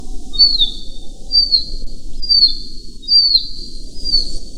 Northern Harrier
VOZ: En los territorios de crφa emite una serie de notas "kek". El llamado en el invierno es una serie de silbidos agudos, "φ-iah".